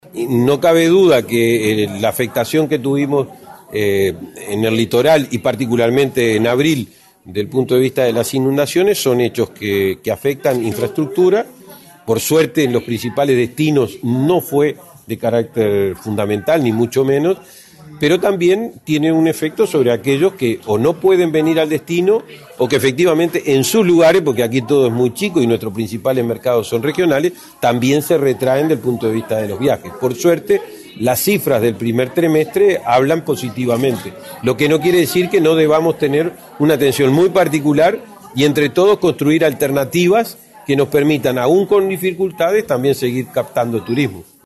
“Desde hace varios años hemos venido construyendo una interacción con las intendencias: un trabajo en el territorio que nos permite que las inquietudes locales se canalicen con antelación a estos encuentros. Aquí abordamos asuntos generales”, explicó el entrevistado.